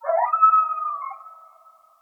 sounds_coyote_howl.ogg